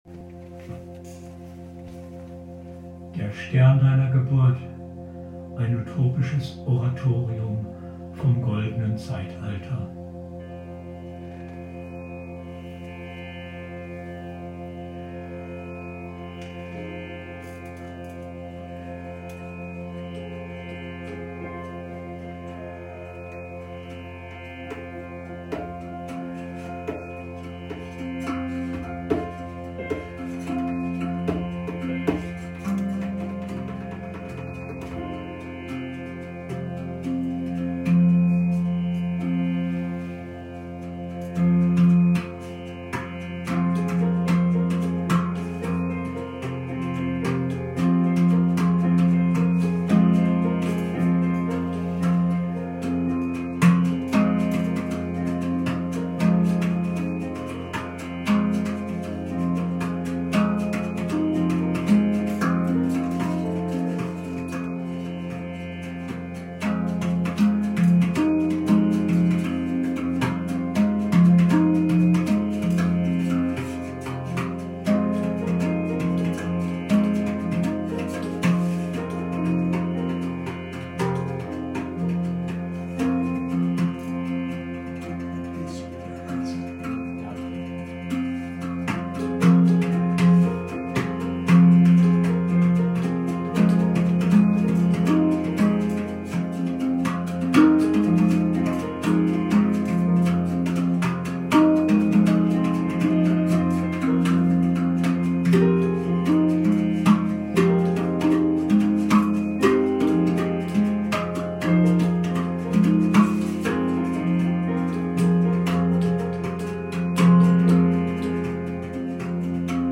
Von einem andern Stern /verbesserte Aufnahme
utopisches Oratorium vom Goldenen Zeitalter, ganz frisch aufgenommen und ersonnen